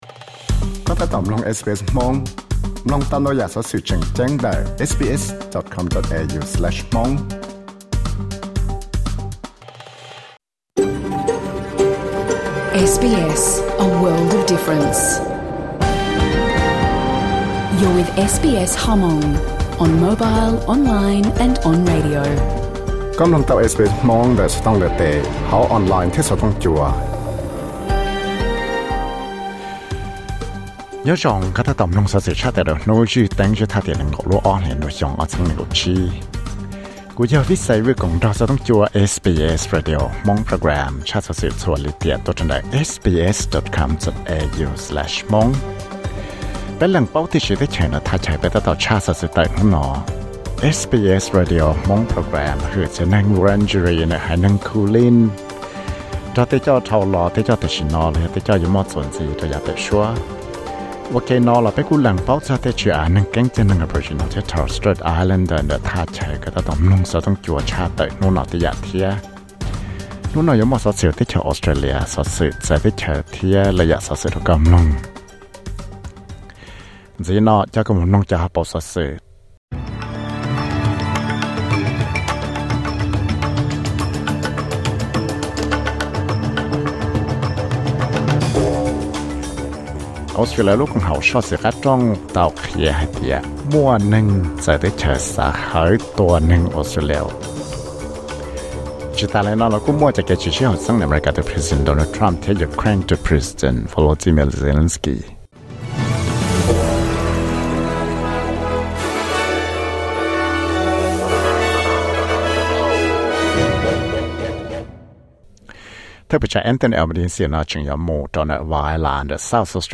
Xov xwm luv Credit